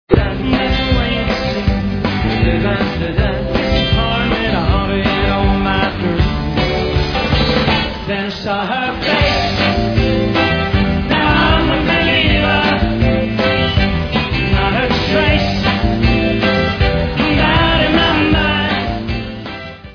Live concert
sledovat novinky v kategorii Rock